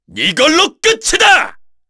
Chase-Vox_Skill4_kr.wav